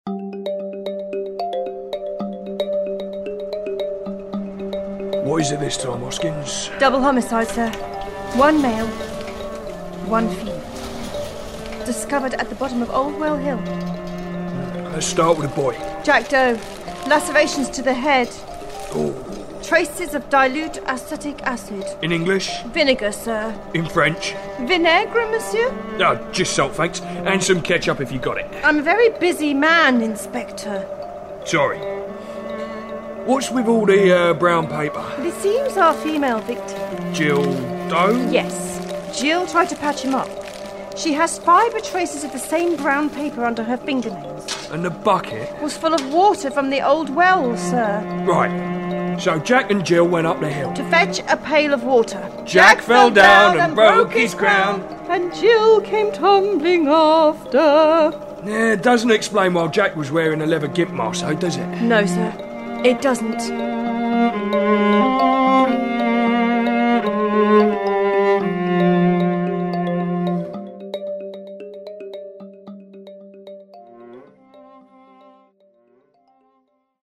A sketch from Episode 6 of comedy podcast 4amcab